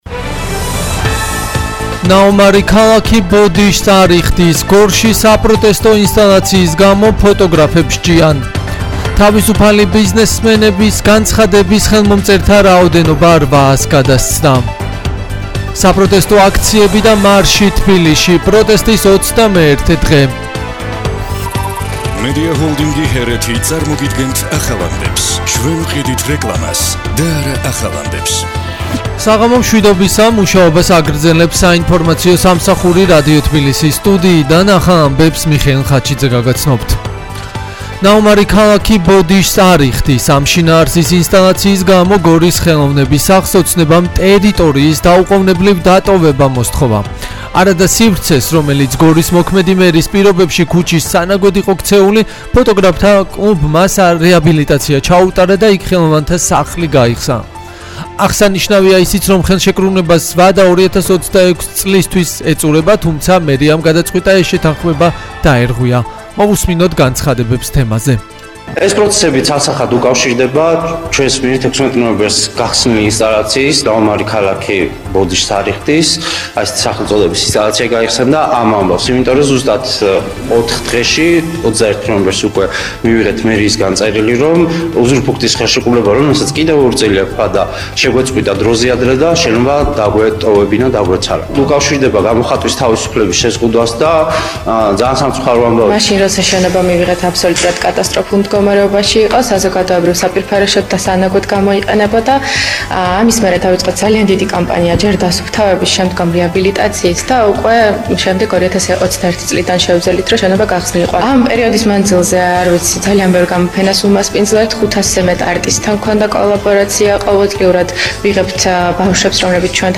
ახალი ამბები 20:00 საათზე